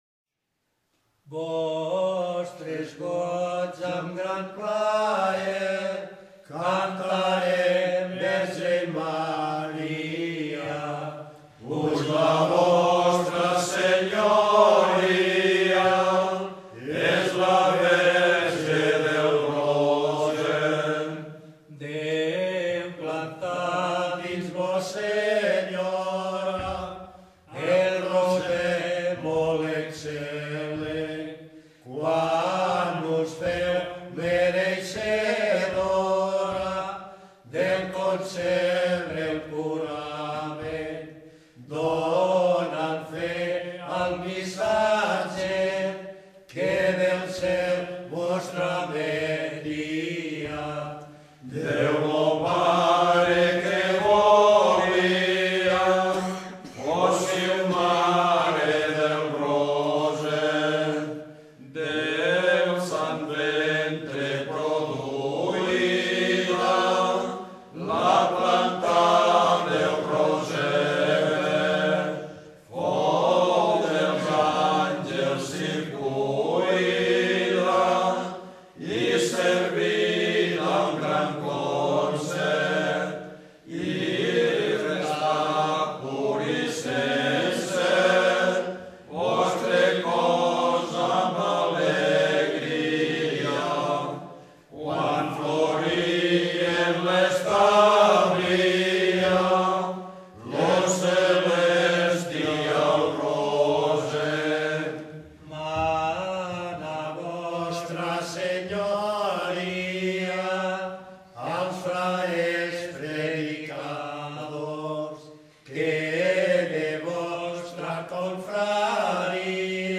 El ritme dels cants sol ser molt irregular, regint-se a la mètrica sil·làbica amb respiracions i pauses força marcades entre cada frase.
Pel que fa la tècnica vocal s’utilitza la veu de pit, creant un timbre molt dens que fomenta la producció d’harmònics.
Malgrat ser molt estesos a tot Catalunya, amb un text provinent del segle XV, mantenen uns girs i un ritme sil·làbic molt peculiars. Les característiques polifòniques són molt similars a l’anterior.